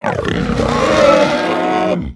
dragonroar.ogg